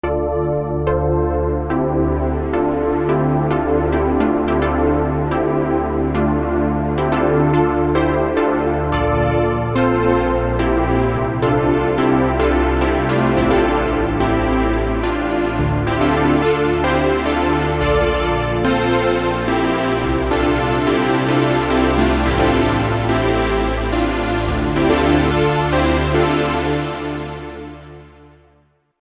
RS6 – Super Pluck Pad | Supercritical Synthesizers
RS6-Super-Pluck-Pad.mp3